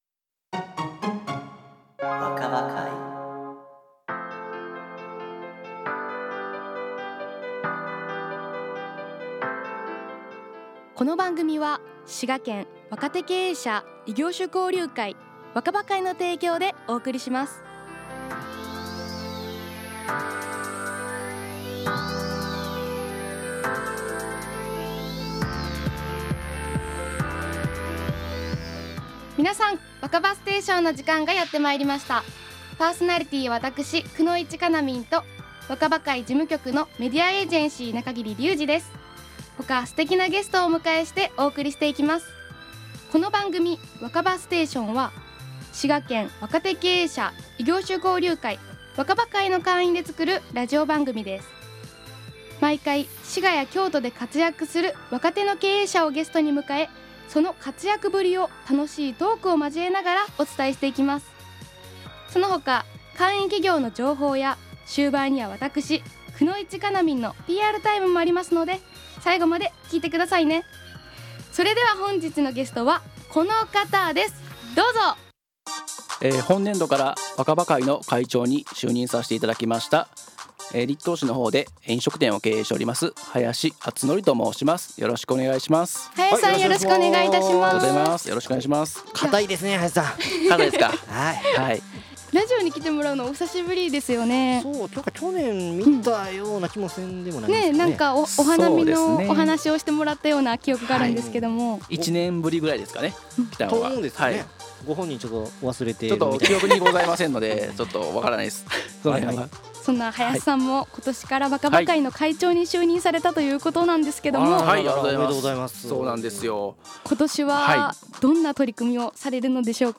本日20時から放送された「Wakaba-Station」を、配信いたします！
わかば会の提供番組「Wakaba-Station」は、毎月第一水曜日の１９時（再放送は24時）から放送していますので、是非お聞きください！